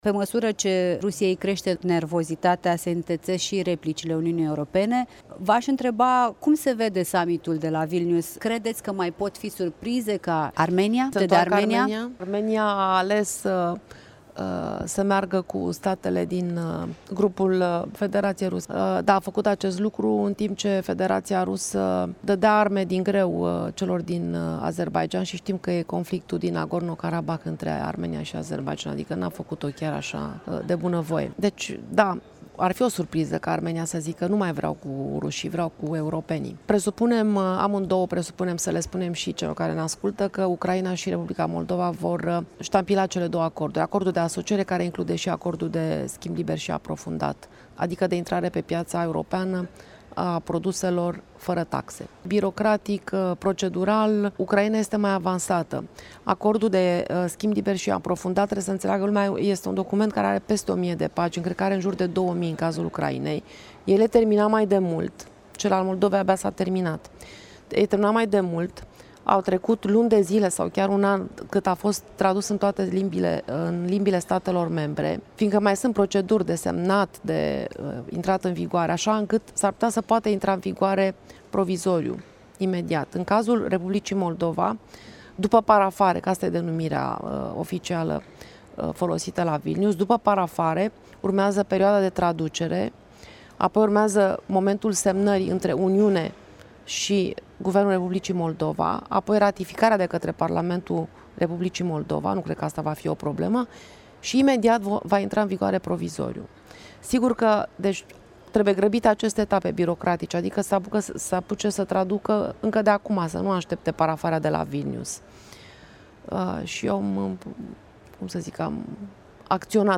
În dialog exclusiv la Strasbourg
discută cu europarlamentara Monica Macovei